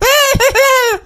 mrp_kill_vo_03.ogg